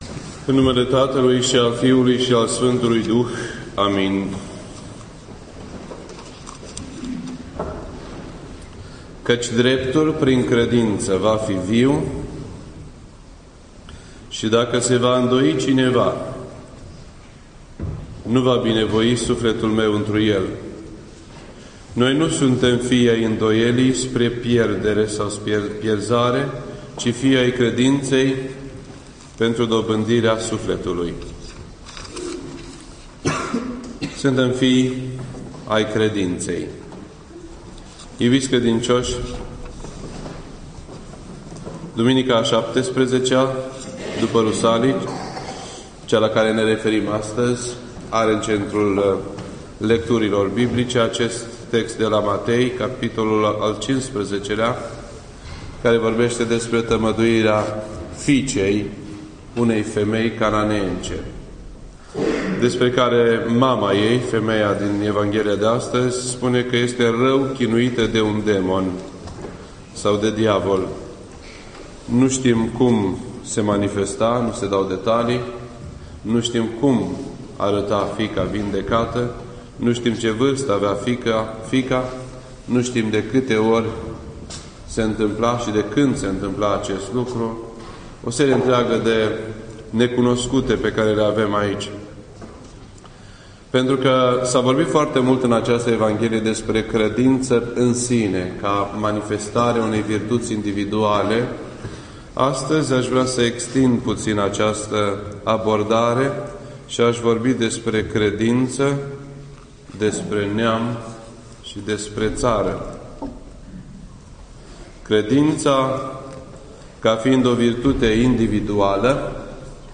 This entry was posted on Sunday, January 15th, 2012 at 8:34 PM and is filed under Predici ortodoxe in format audio.